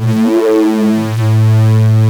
OSCAR 10 A2.wav